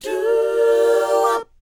DOWOP C 4G.wav